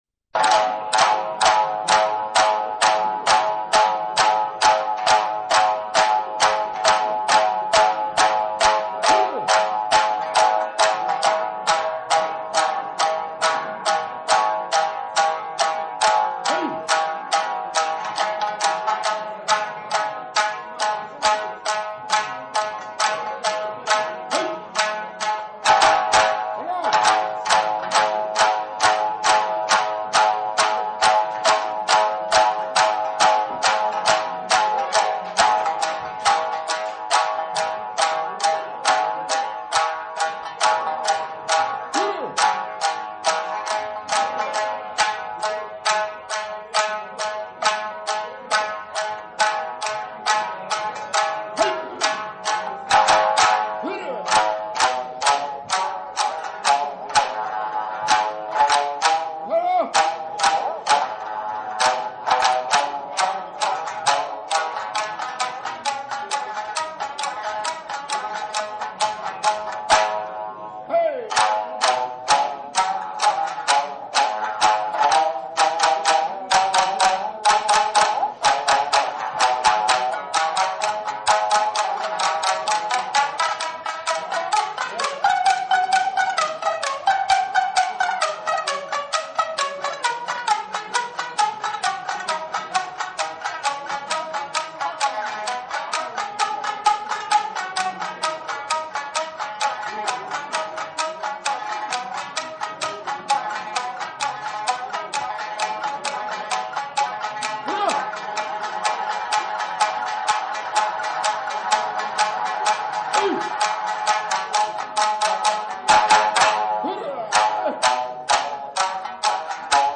ensemble.mp3